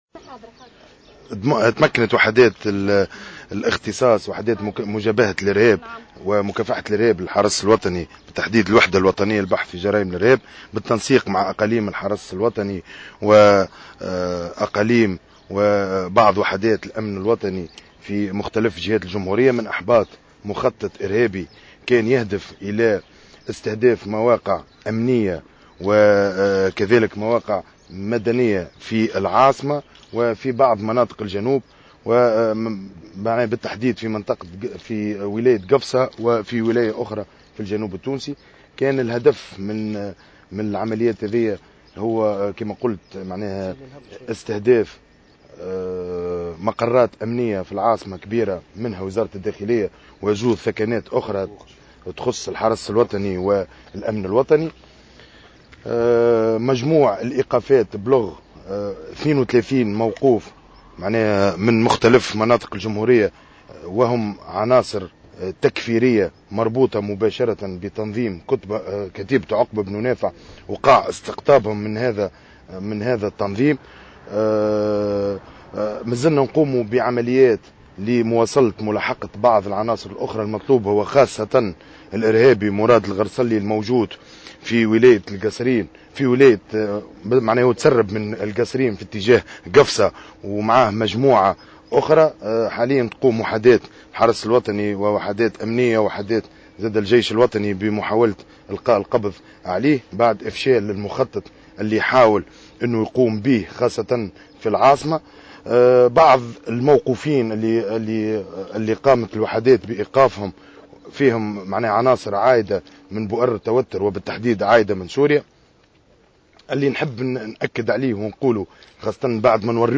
خلال ندوة صحفية اليوم السبت